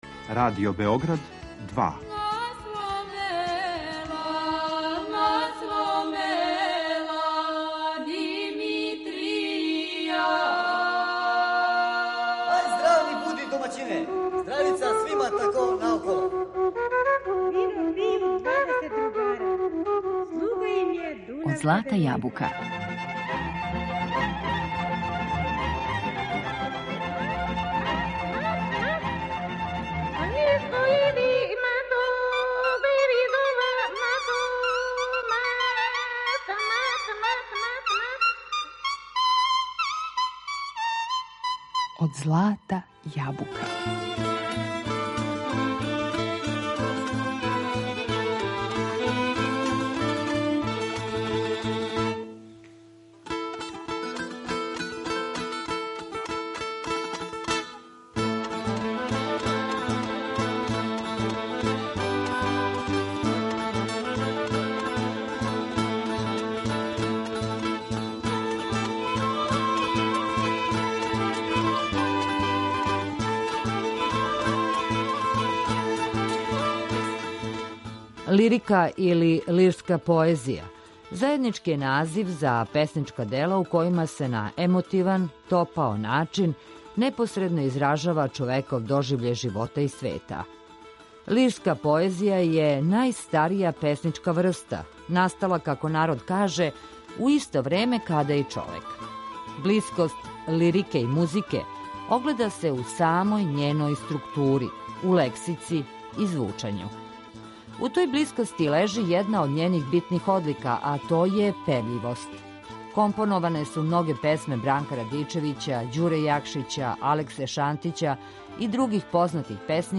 Данас говоримо о љубавној лирици, али са посебним освртом на мотив момка и девојке у народној поезији, а све приче у емисији Од злата јабука , као и увек, осликаћемо народним песмама и играма.